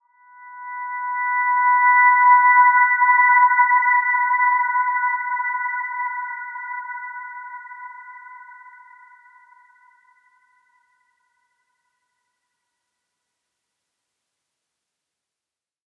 Dreamy-Fifths-B5-mf.wav